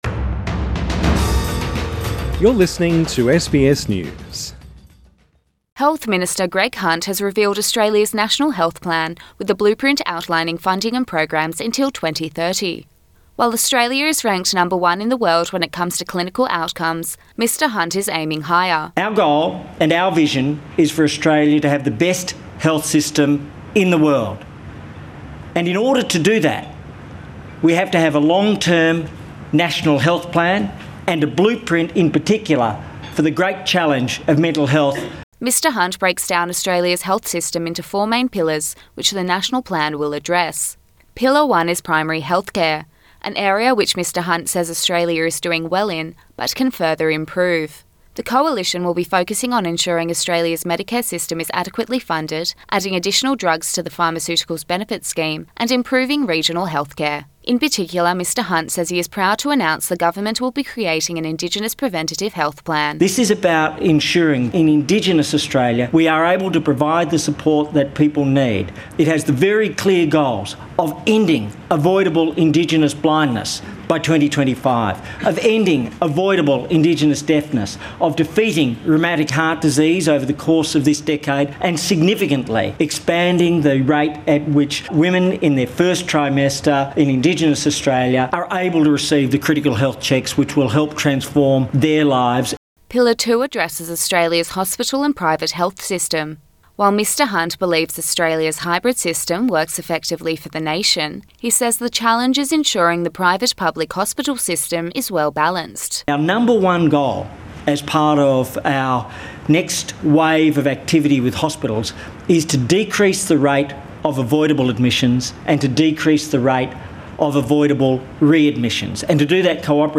Australian Health Minister Greg Hunt addresses the National Press Club in Canberra Source: AAP